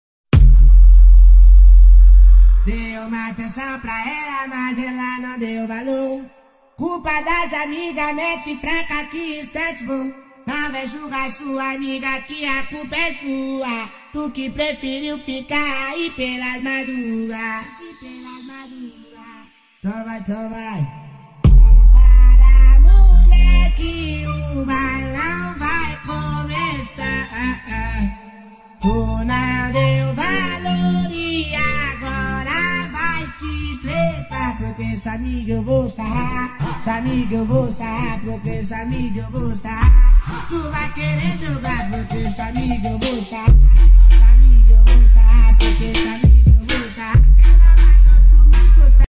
ʙᴀꜱꜱ ʙᴏᴏꜱᴛᴇᴅ
#8daudio